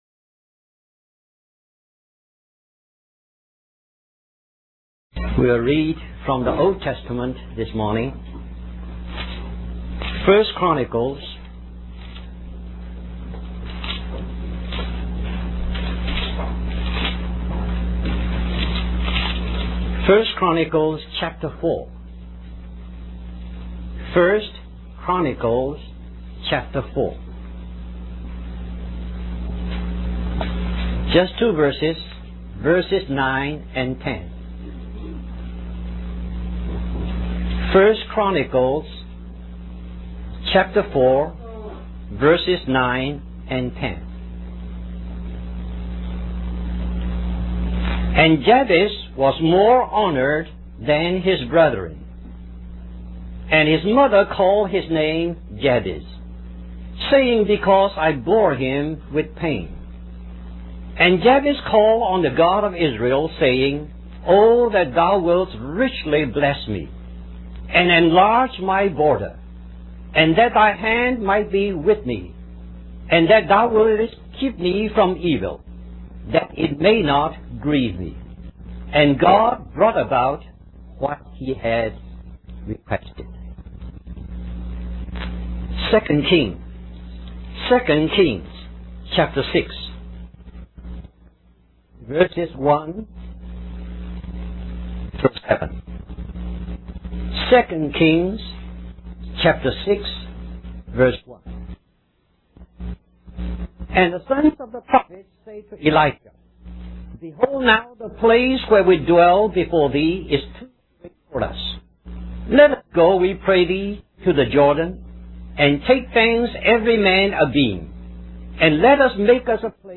A collection of Christ focused messages published by the Christian Testimony Ministry in Richmond, VA.
We apologize for the poor quality audio